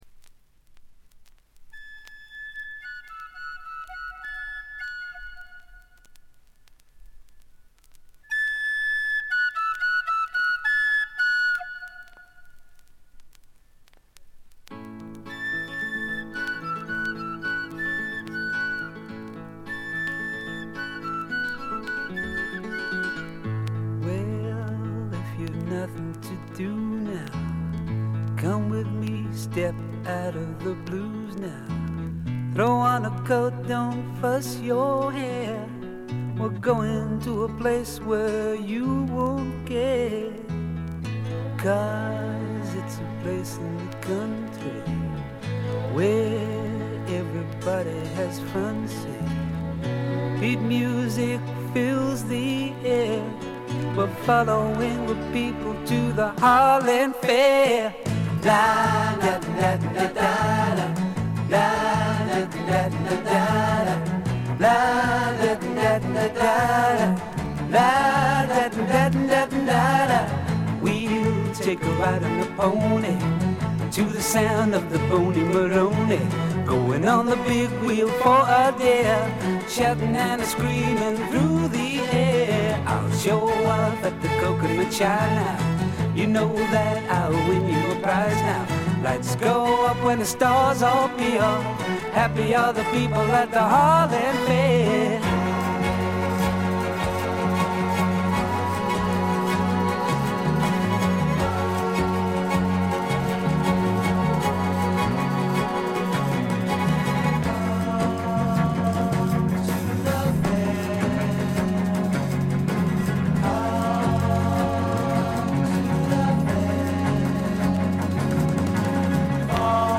A1中盤周回ノイズ10回ほど。ところどころでチリプチ。
ソフトサイケ、ドリーミーポップの名作。
試聴曲は現品からの取り込み音源です。